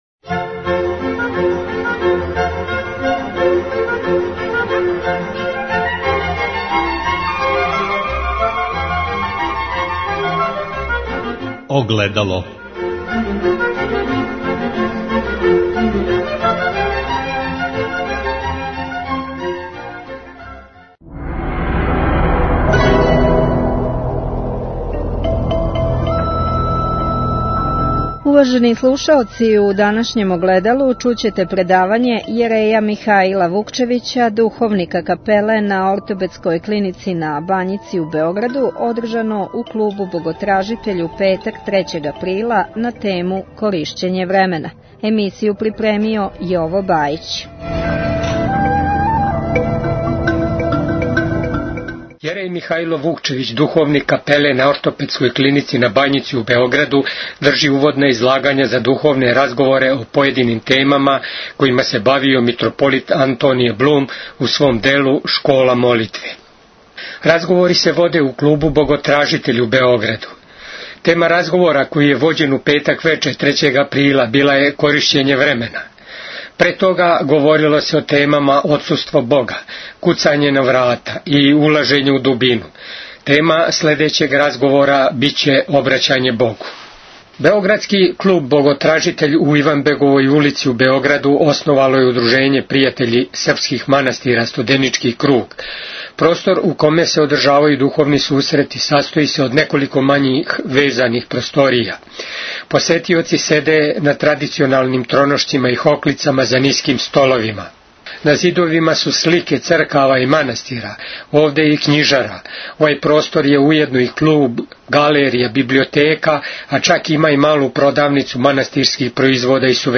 Издаваштво Канадске епархије Tagged: Огледало 52:48 минута (9.07 МБ) На Божићном сајму у Умјетничком павиљону "Цвијета Зузорић" у Београду, 30. децембра, представљено је издаваштво Канадске епархије.
Пјесници су читали своје стихове.